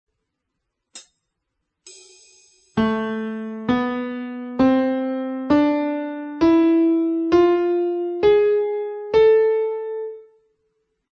qui la scala di La minore armonica
scala_min_armonica_(64,kb.mp3